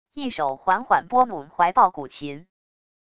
一手缓缓拨弄怀抱古琴wav音频